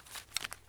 mp153_reload_in.ogg